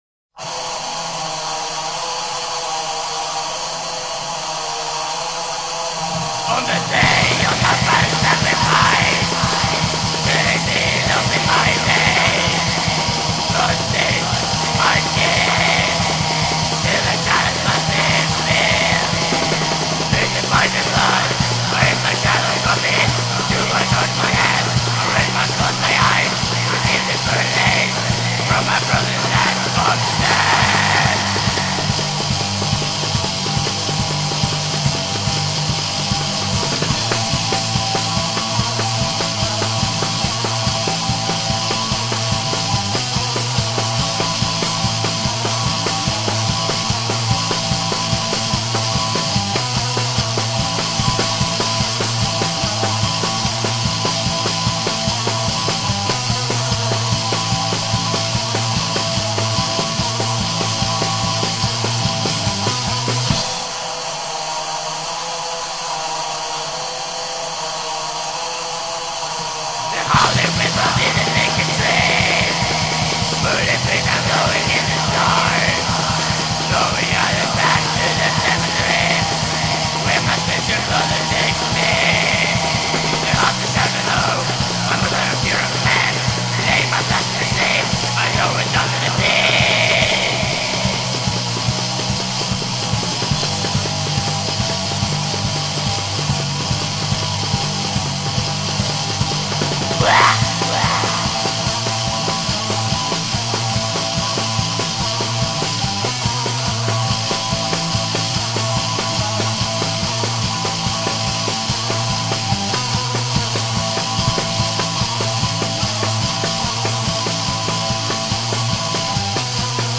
بلک متال
black metal